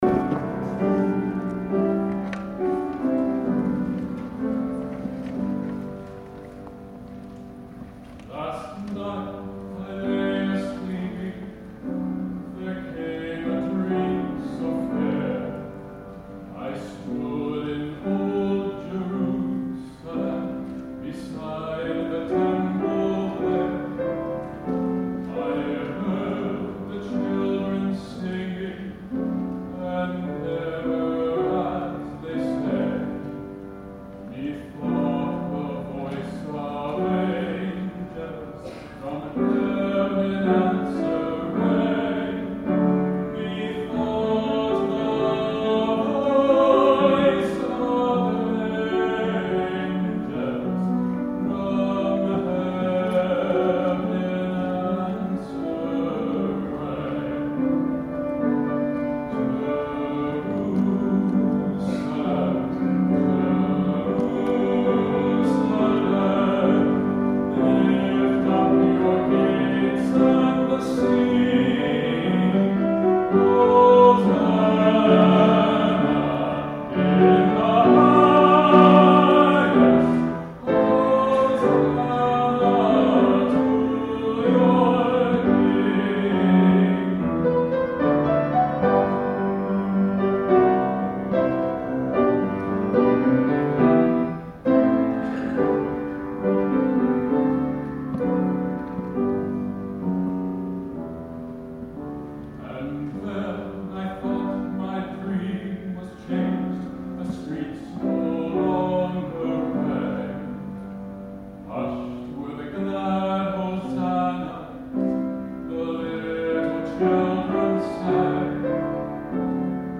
the Holy City: Another Gorgeous Song Recorded at the Church Service I Attended This Evening
This song, which was originally a Victorian ballad, scared the bloody hell out of me when I was twelve or thirteen - especially the second verse!
Sorry for the mic clippage again; I've still not quite got this mic gain thing down pat.